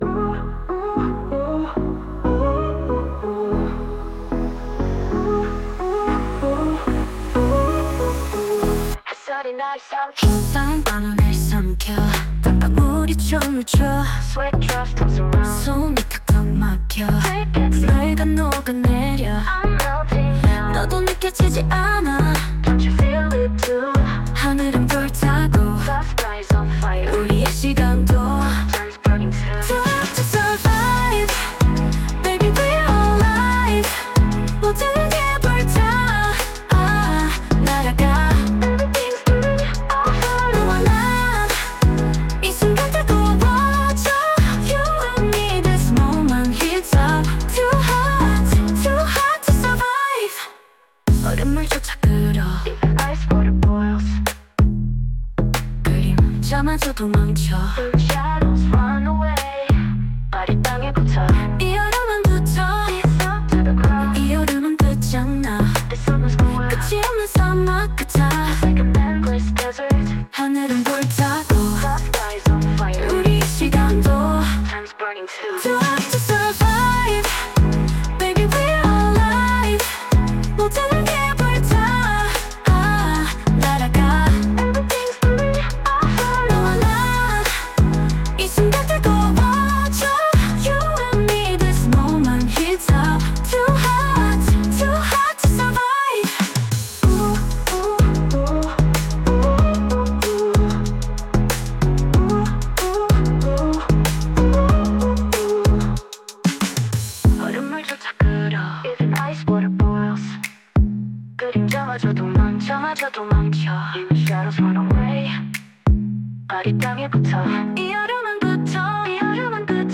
수노에게 더위를 케이팝 스타일로 표현해달라고 했는데...가사 내용만 덥네요~